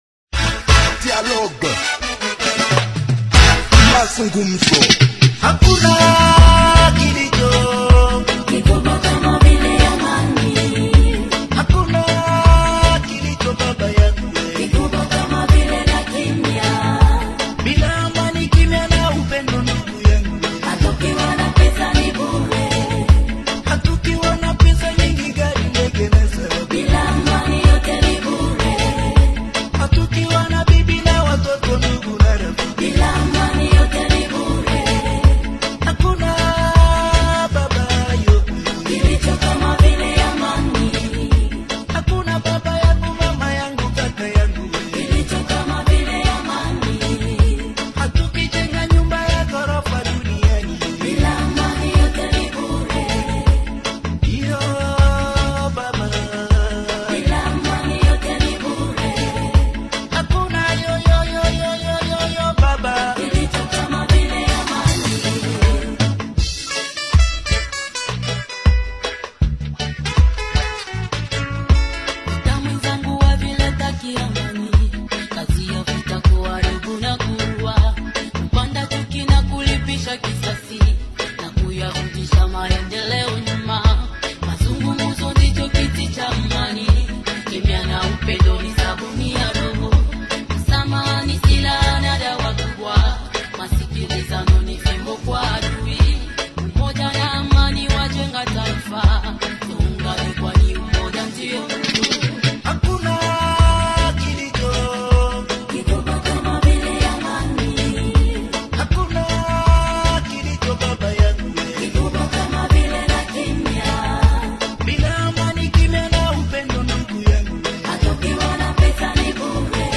Journal en Français du 15 Aout 2025 – Radio Maendeleo